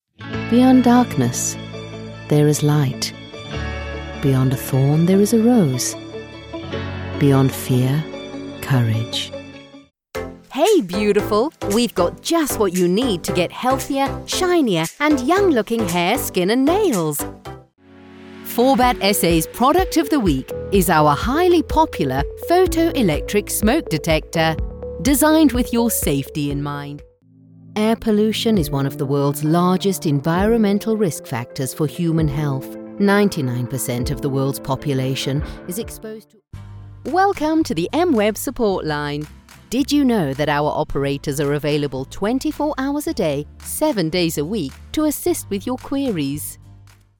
Inglês (sul-africano)
Calma
Amigáveis
Natural